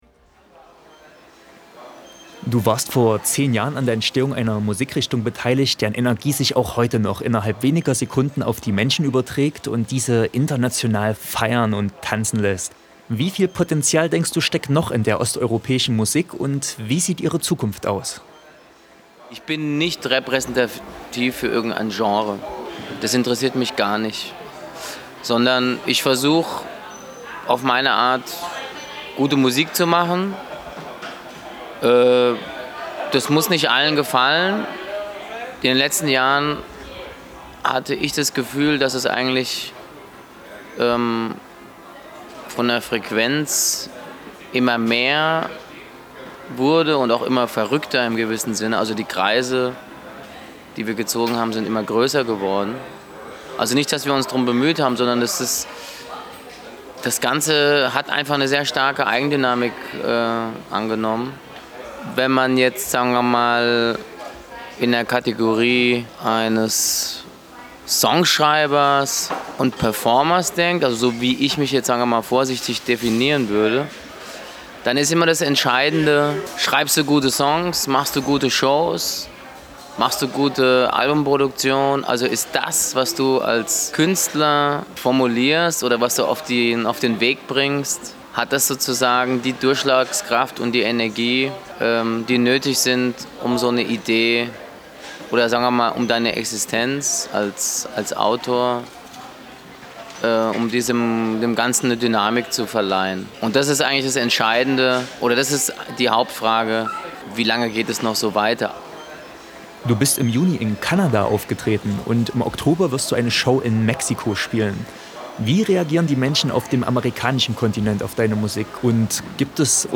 Zum TFF Rudolstadt 2012 trafen wir Stefan Hantel, „Shantel“ zum Interview.